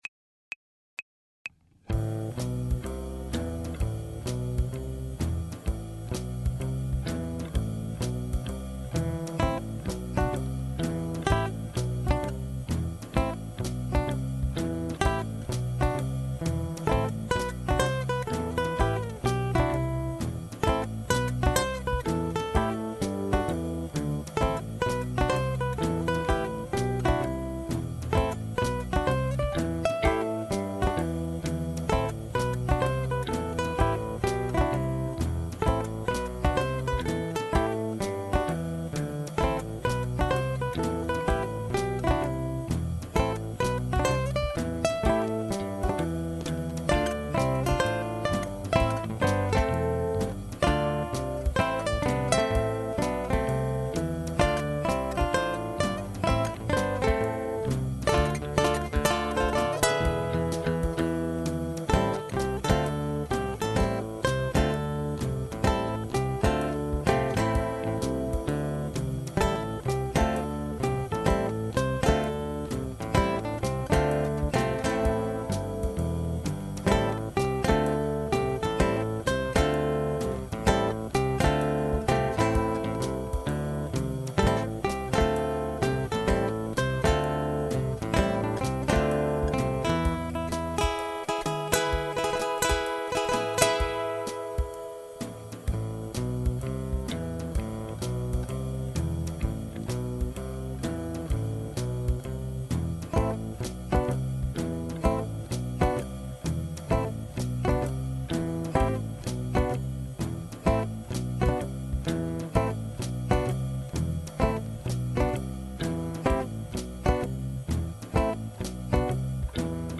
guitar ensemble arrangements